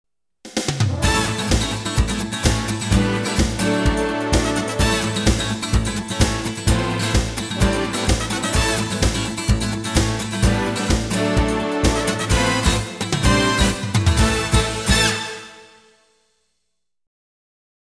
激情的背景音乐